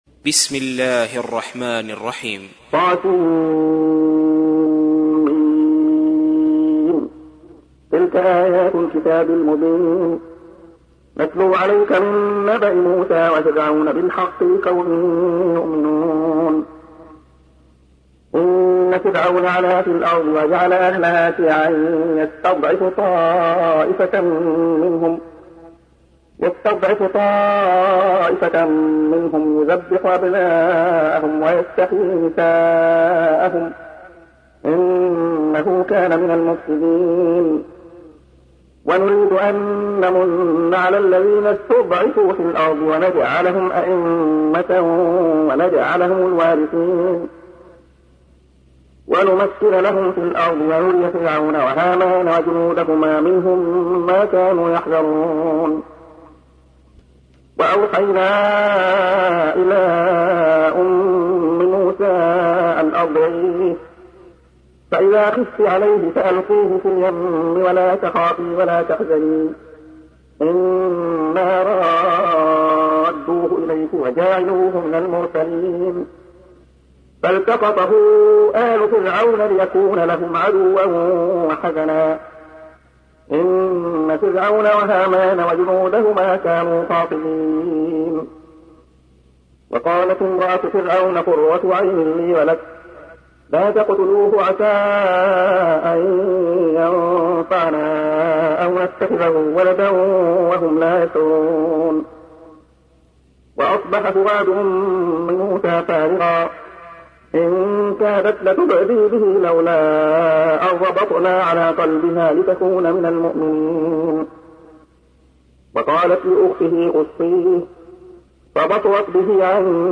تحميل : 28. سورة القصص / القارئ عبد الله خياط / القرآن الكريم / موقع يا حسين